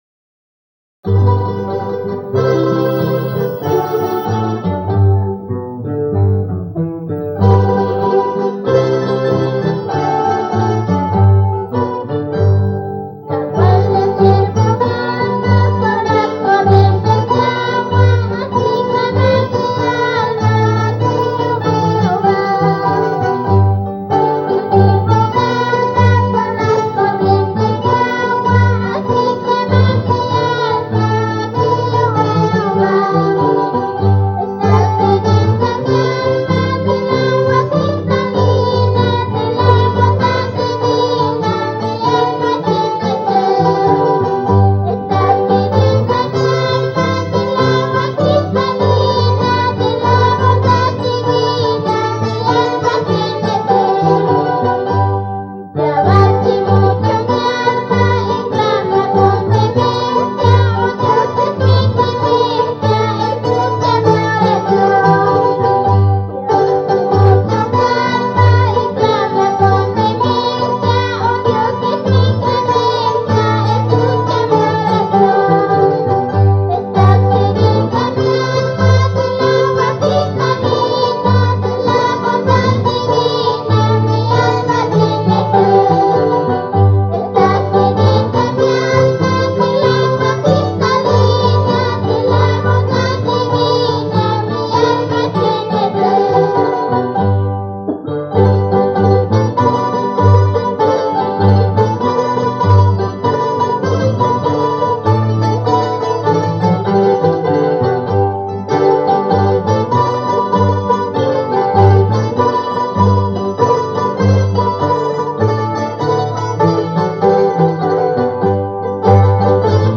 (Mandolina)
Guitarra
Charango
Vocalista